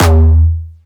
Jumpstyle Kick Solo